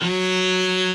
b_cello1_v100l4-3o4fp.ogg